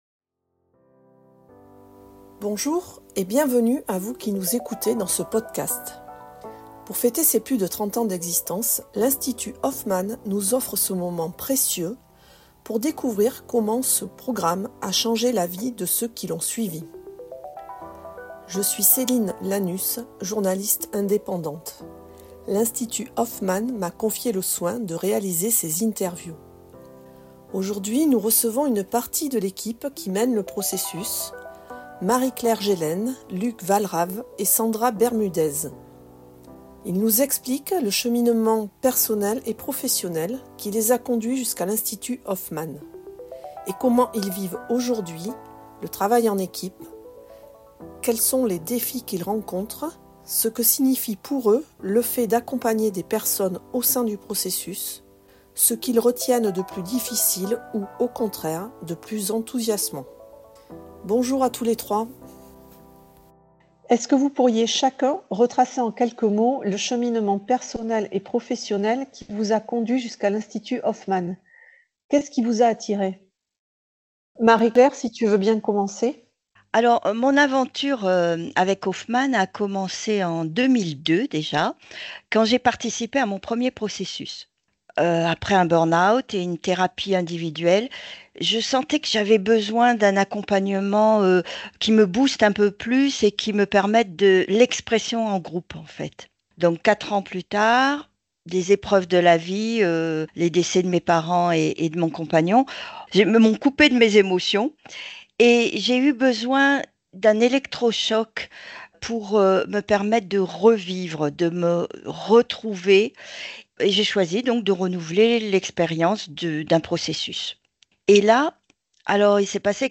Interview Équipe Hoffman
processus-hoffman-france-interview-equipe-2025.mp3